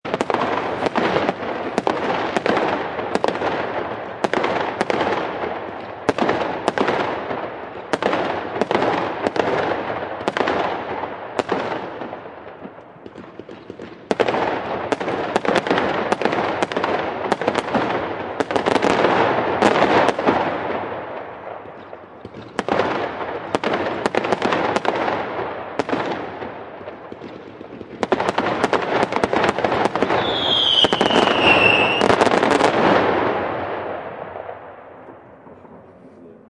Download Fireworks sound effect for free.
Fireworks